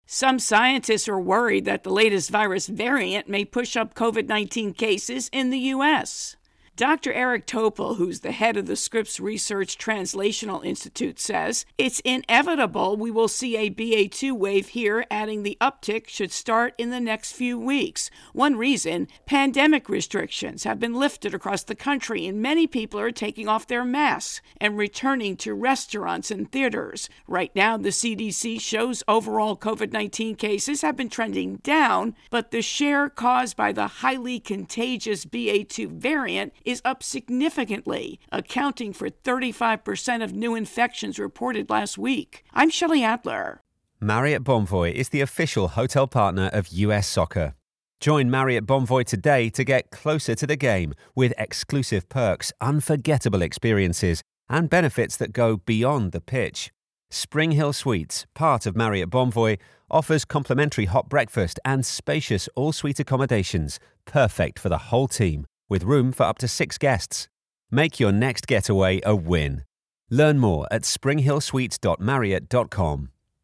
COVID INTRo and voicer